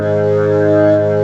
55o-org04-G#1.wav